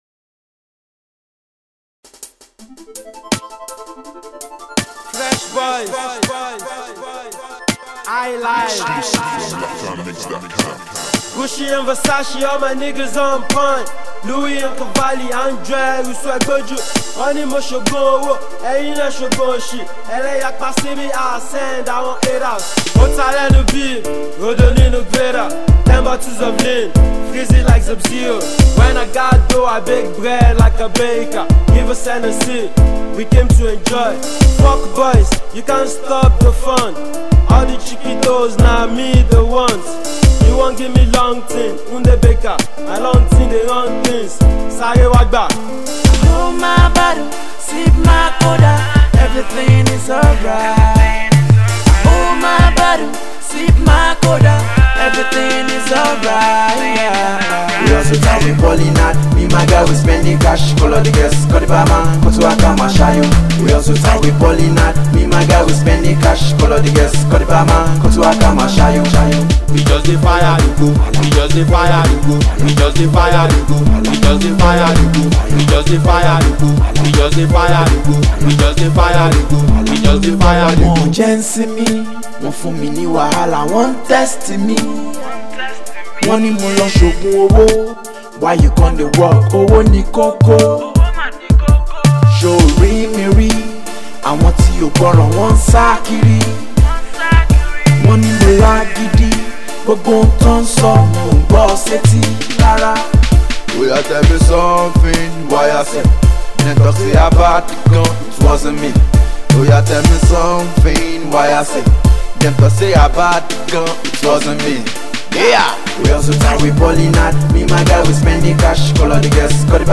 Rap
Street Rapper
It would have been better had he worked on his Flow